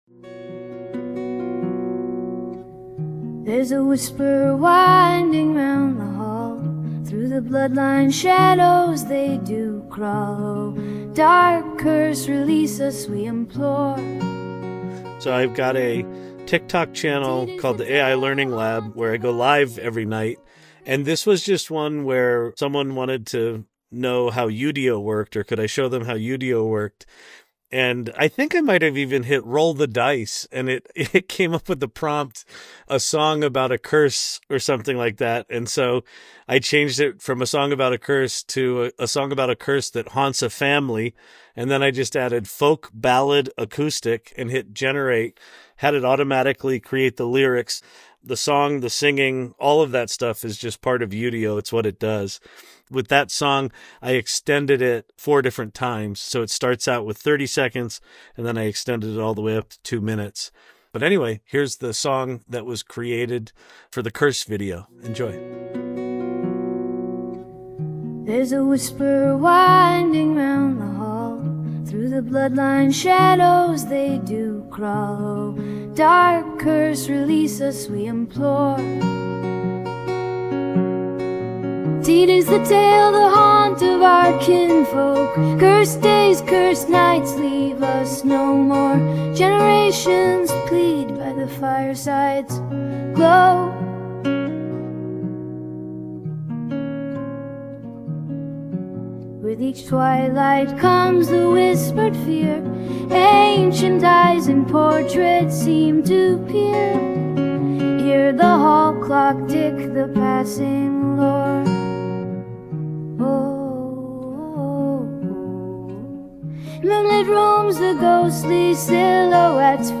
*Believe it or not, this song was created entirely using the AI tool Udio.*